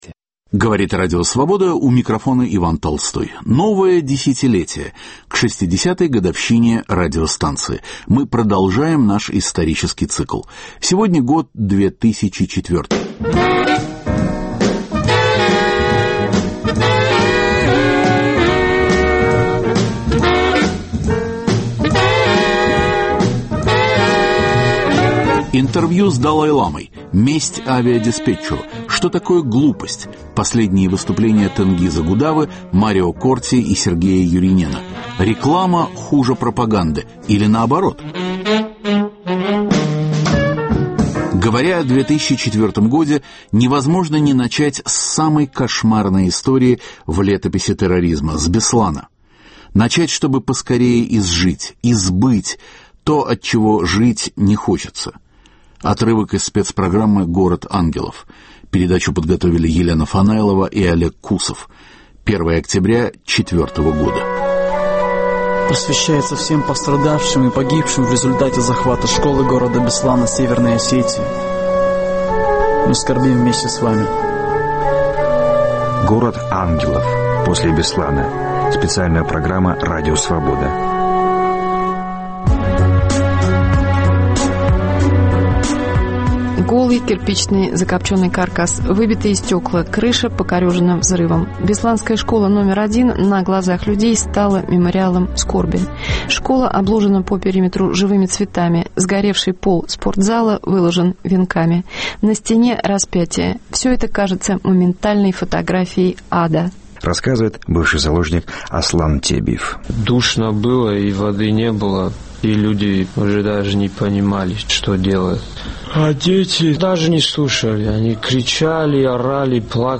Интервью с Далай-ламой.